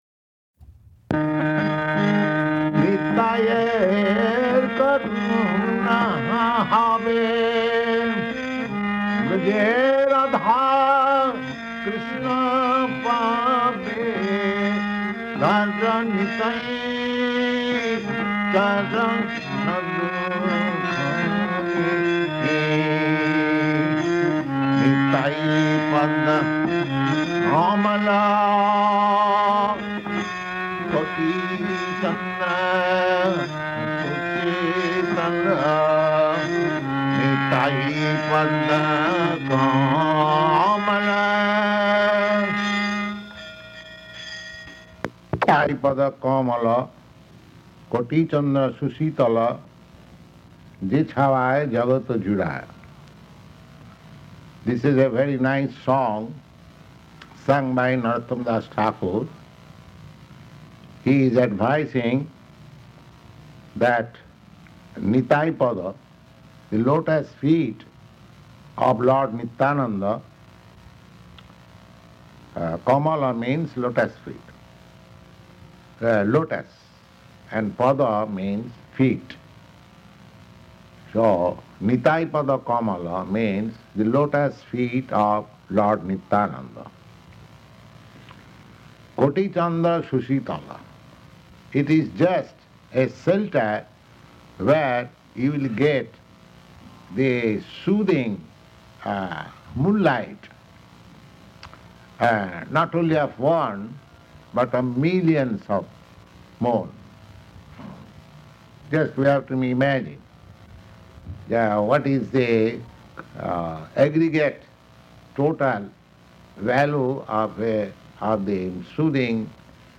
Type: Purport
Location: Los Angeles
[sings: Nitāi-pada-kamala ]